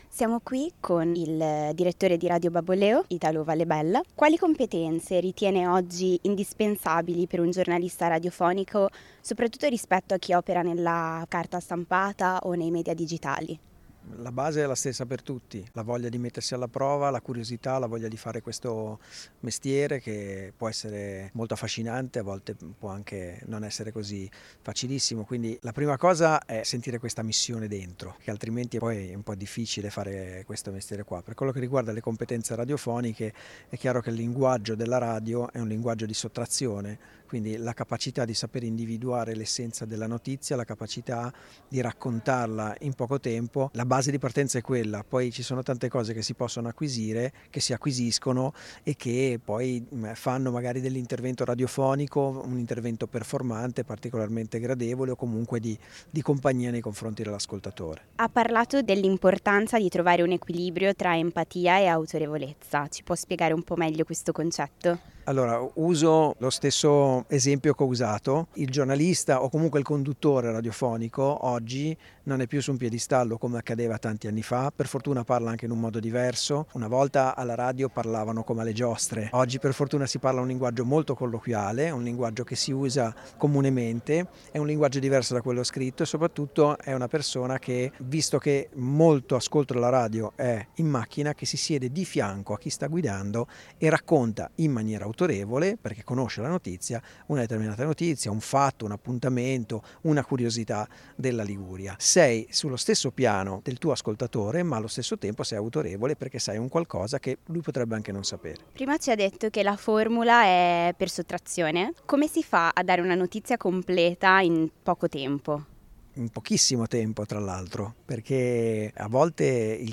In questa intervista esclusiva